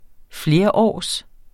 Udtale [ ˈfleɐ̯ˌɒˀs ]